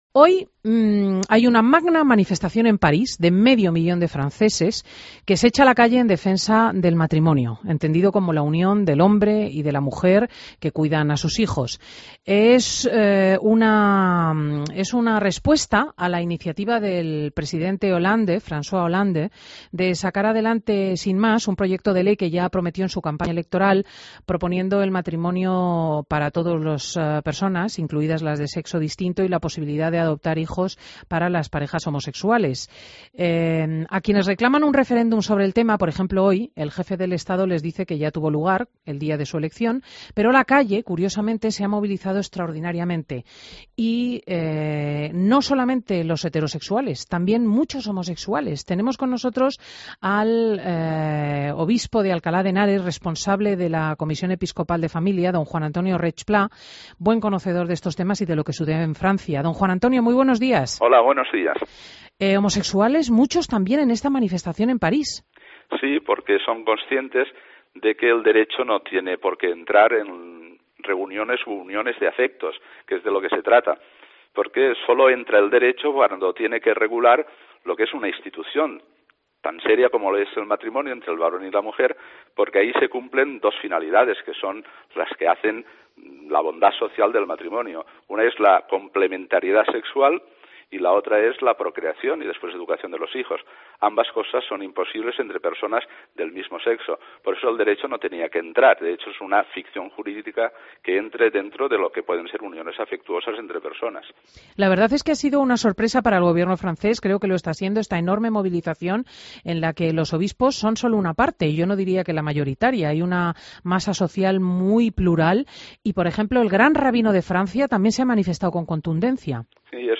AUDIO: Escucha la entrevista con el obispo de Alcalá de Henaras, Juan Antonio Reig Plá